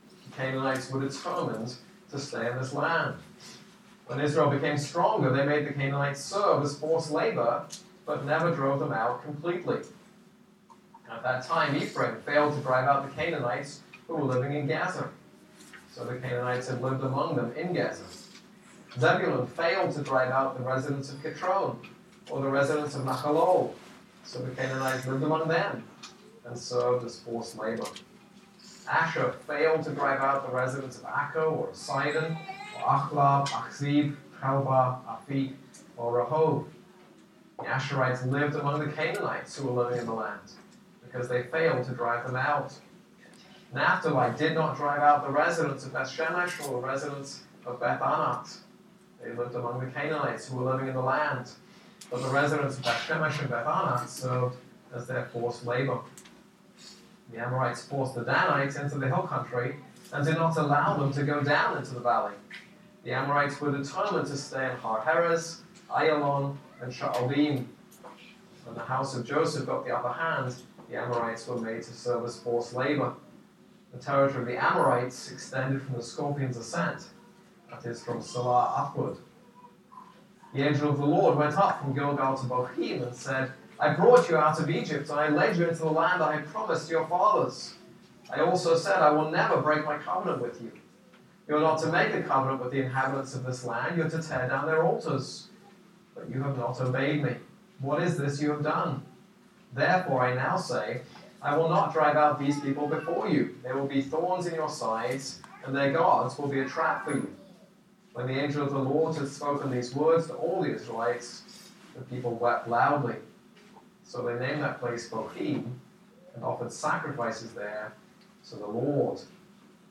This is a sermon on Judges 1:22-2:5.